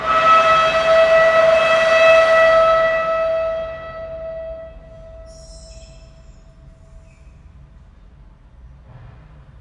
描述：在Baschet Sound Sculpture中演奏弓，木和金属的声音